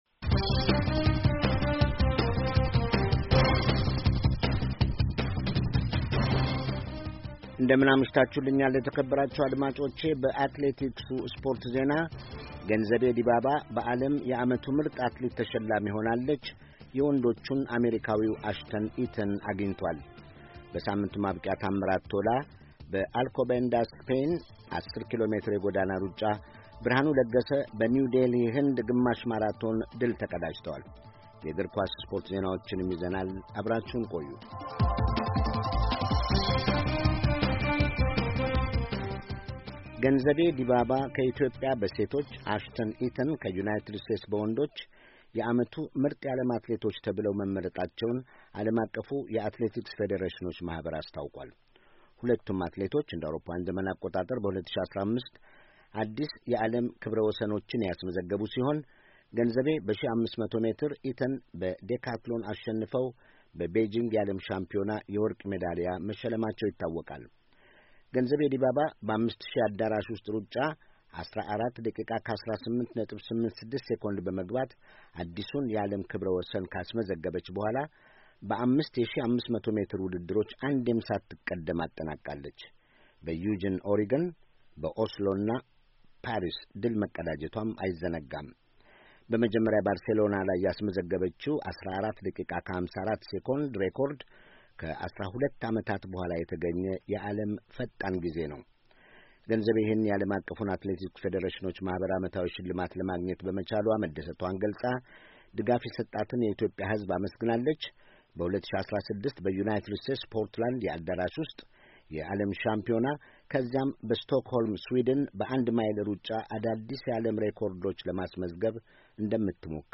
ሳምንታዊ የስፖርት ዜና